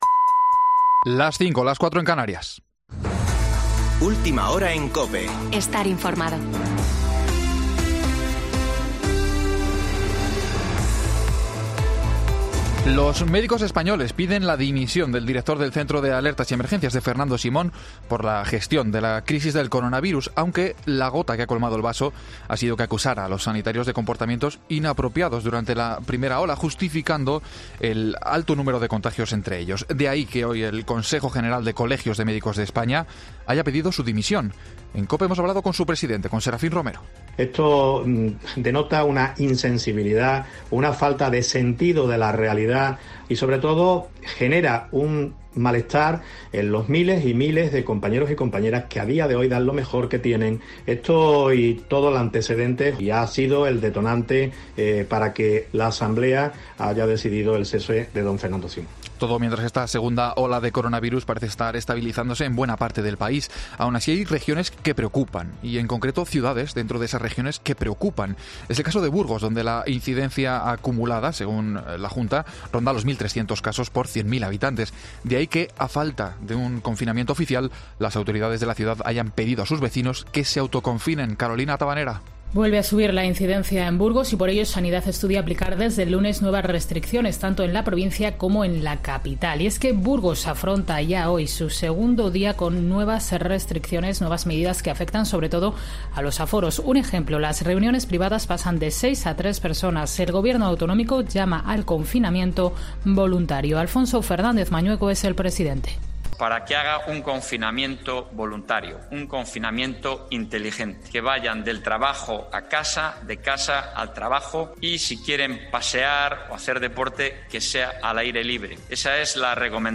Boletín de noticias de COPE del 14 de noviembre de 2020 a las 17.00 horas